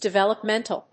音節de・vel・op・men・tal 発音記号・読み方
/dɪvèləpméntl(米国英語), dɪˌvelʌˈpmentʌl(英国英語)/